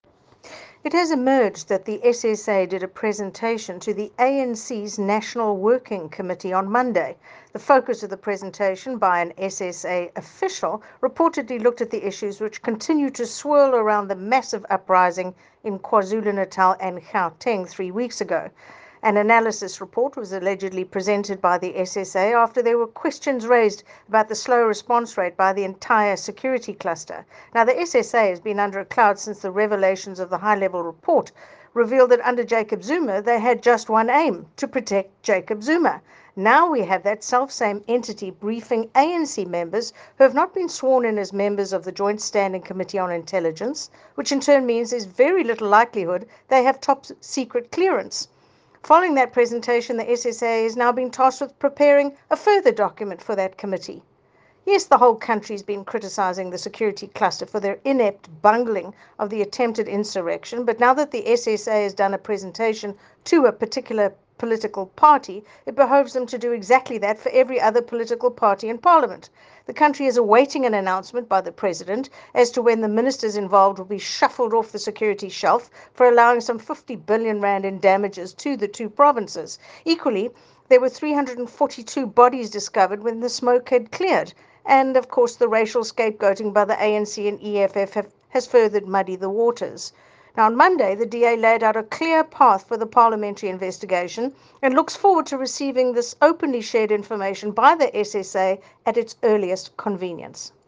soundbite by Dianne Kohler Barnard MP.